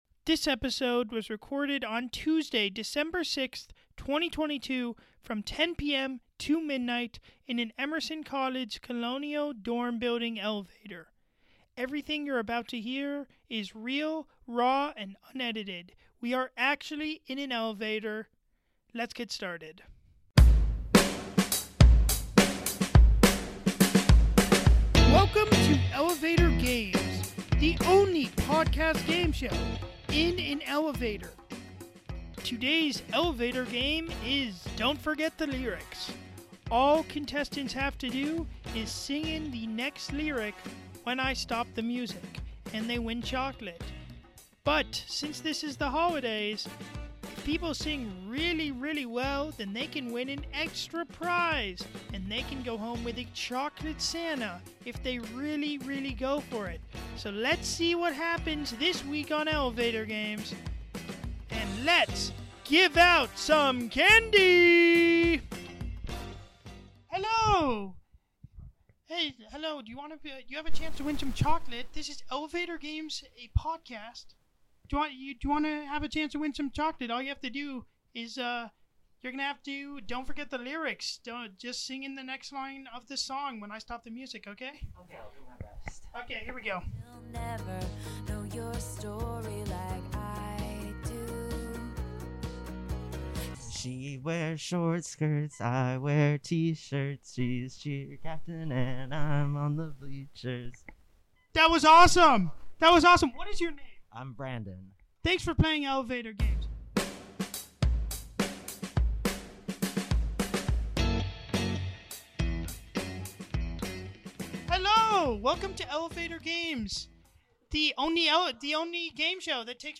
You read that right, we made people sing in the elevator!